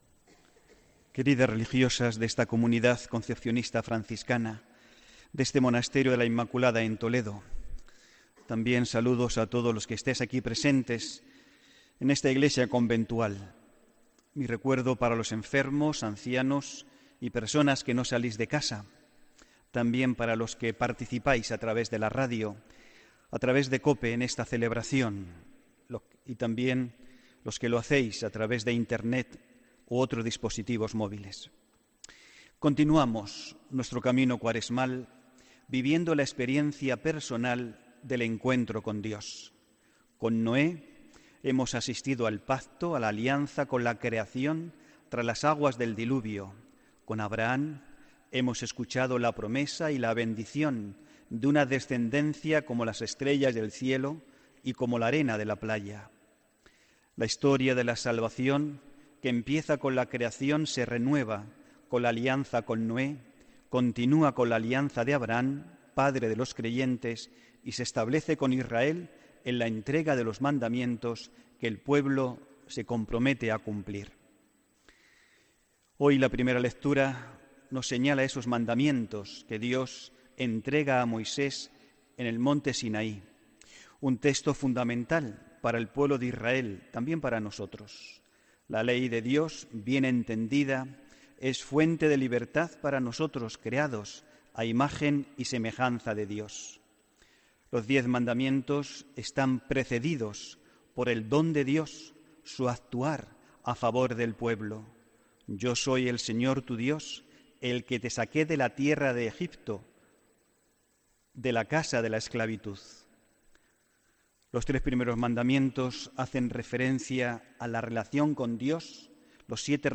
HOMILÍA 4 MARZO 2018